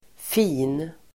Uttal: [fi:n]